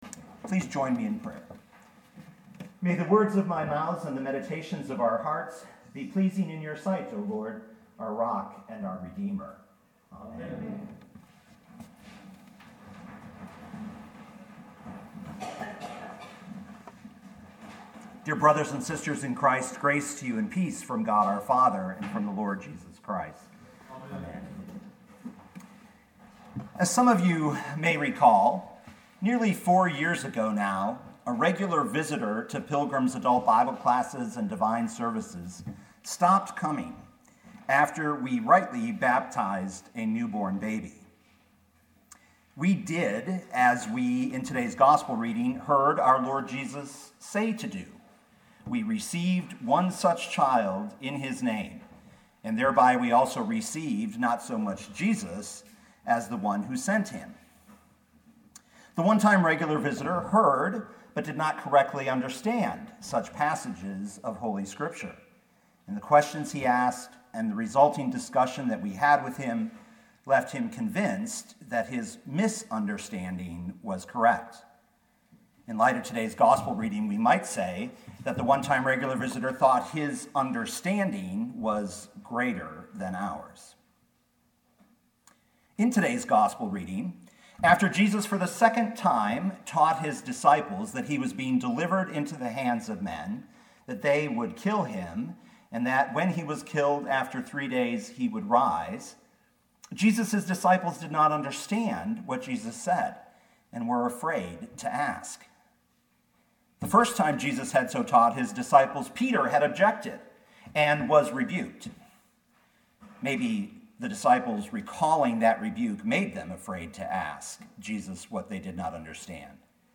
2018 Mark 9:30-37 Listen to the sermon with the player below, or, download the audio.